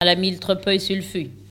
Localisation Sallertaine
Locutions vernaculaires